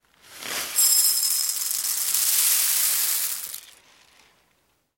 Звуки крупы: рис в керамической ёмкости